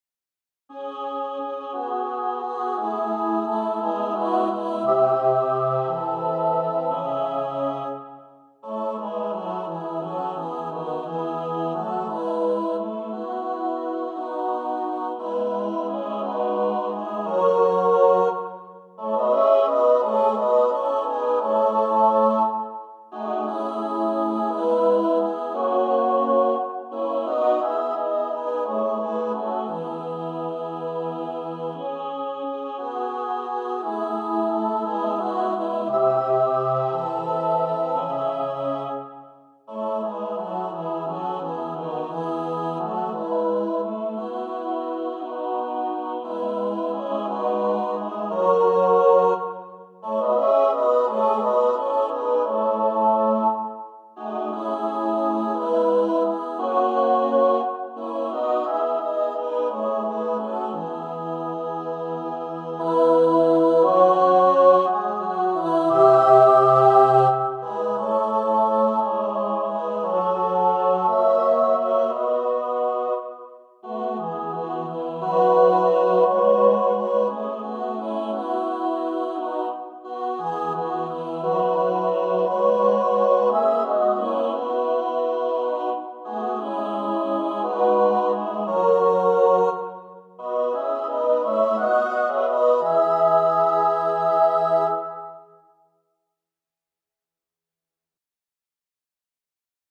MIDI recording
Instrumentation: Unaccompanied SATB chorus